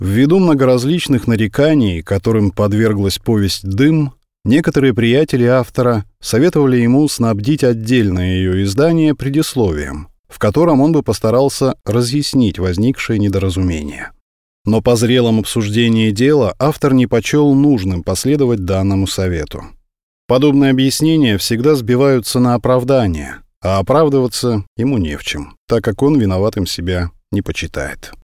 Аудиокнига Предисловие к отдельному изданию «Дыма» | Библиотека аудиокниг